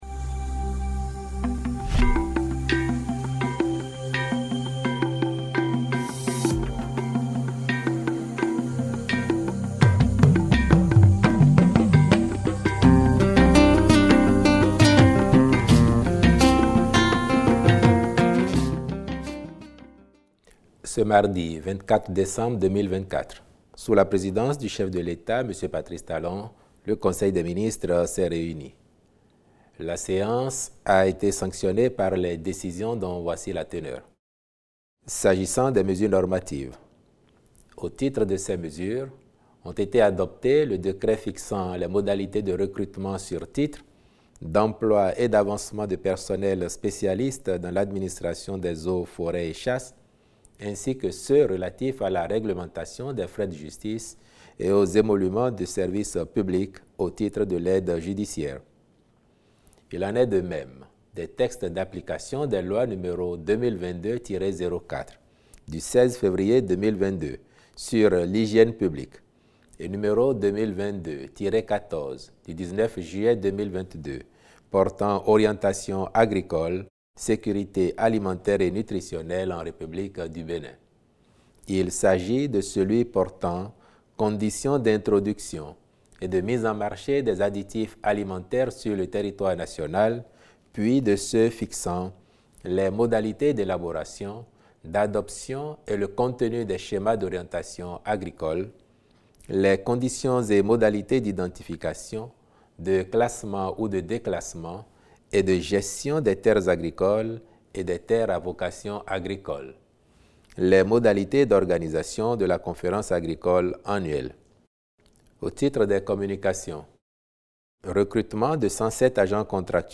A cette décision s’ajoute l’annonce de recrutement de 107 agents contractuels de droit public de l’Etat au titre de l’année 2024. Les détails des décisions prises au cours de cette réunion hebdomadaire sont contenus dans cet élément audio présenté par Wilfried Léandre Houngbédji, le porte-parole du gouvernement.